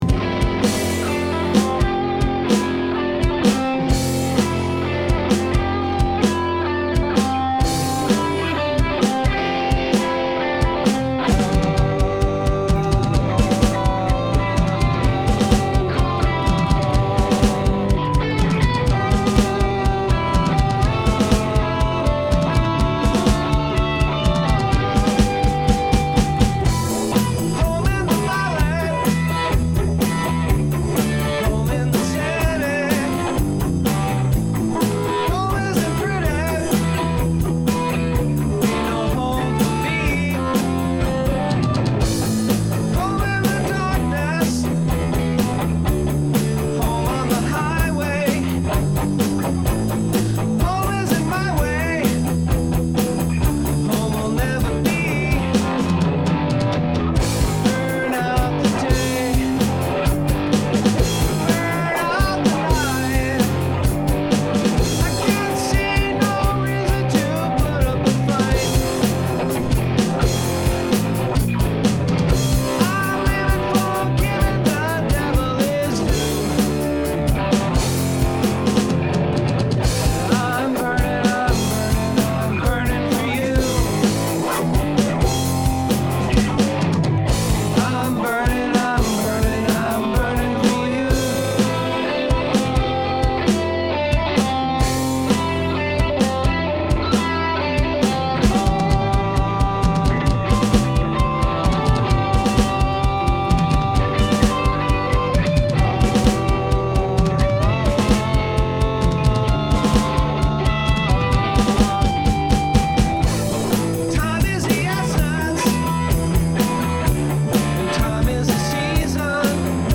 Room Mix.mp3